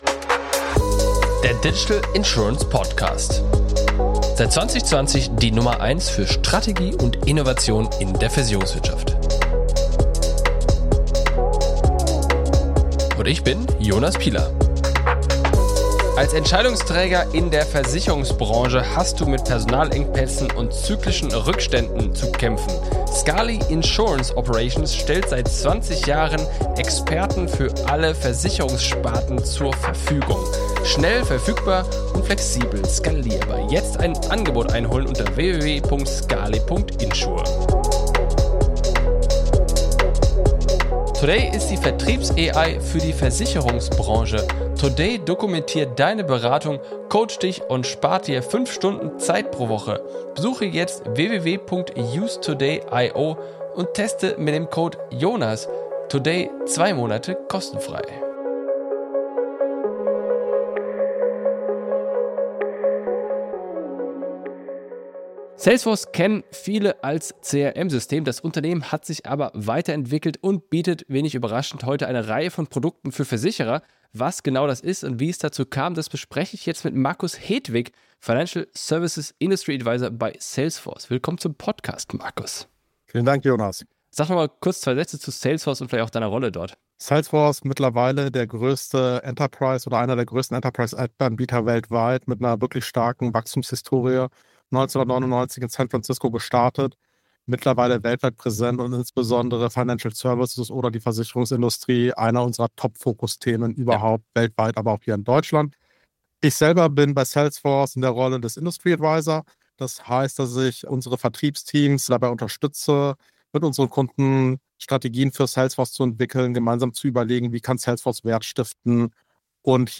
Hier sind fünf Highlights aus unserem Gespräch: